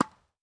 Ball Hit Normal Tennis.wav